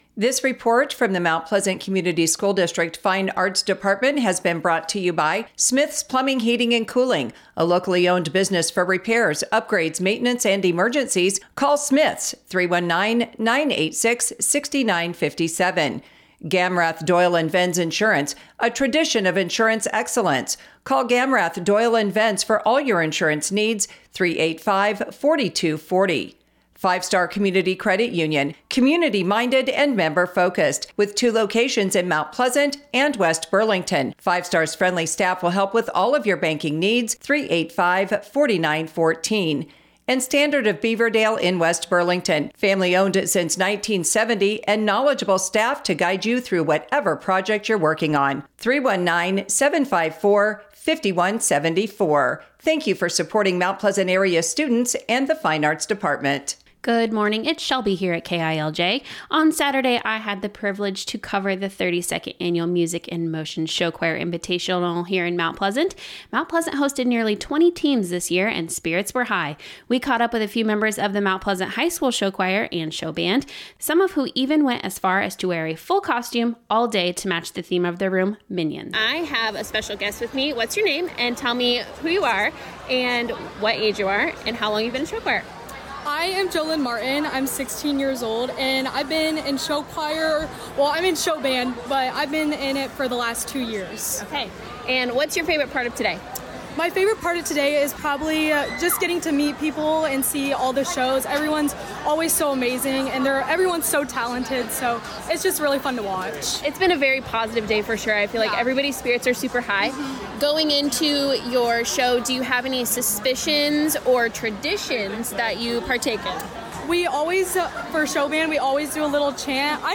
The invitational took place Saturday, February 7, 2026 at Mount Pleasant High School and featured a full day of performances from across the region.